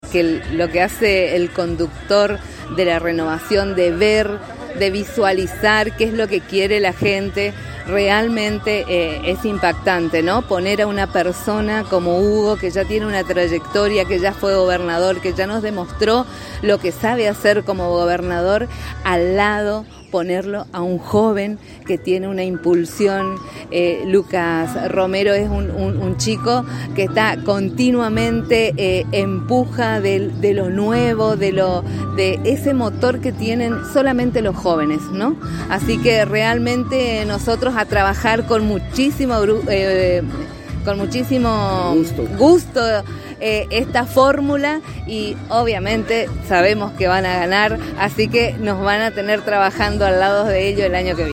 En diálogo exclusivo con la ANG en la Expo Mujer 2022 en Apóstoles, la Ministra de Acción Cooperativa Liliana Rodriguez, manifestó con relación a la fórmula de la Renovación para el 2023 que es espectacular lo que ha hecho el Conductor de la Renovación Carlos Rovira de convocar a Hugo Passalacqua, un hombre de experiencia en el arte de gobernar junto a un joven con mucha impronta y empuje. Resaltó la Ministra que militará y trabajará con mucho gusto por esta fórmula que seguramente triunfará en el 2023.